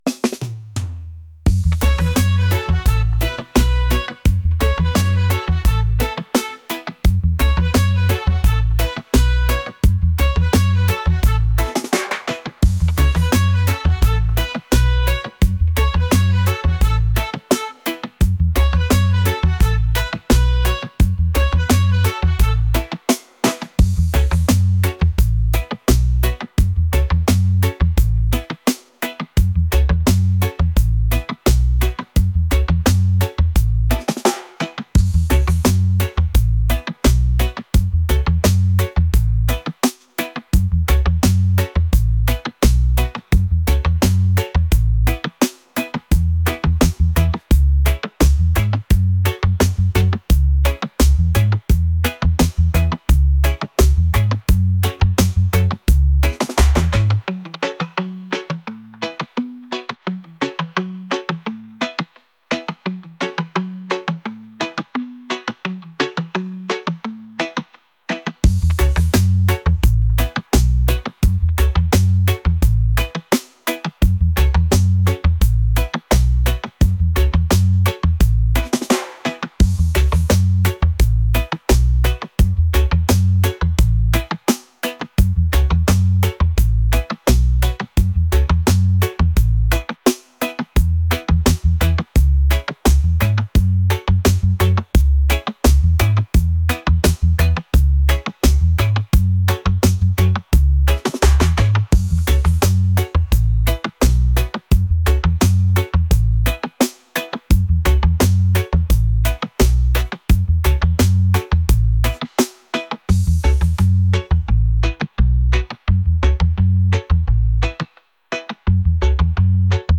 reggae | latin | folk